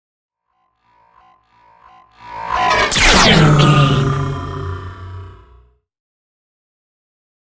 Sci fi shot whoosh to hit 723
Sound Effects
futuristic
intense
woosh to hit